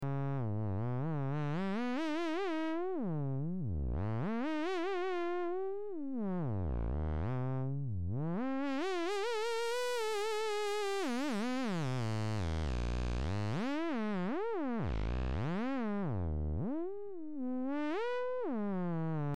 同じSynthDefが起動(常駐)しているところで、以下の例では、ランダム間隔であるスケールからランダムに選んでランダムな定位で発音している。 こんな簡単なものでアルゴリズミック作曲が出来る、というわけである。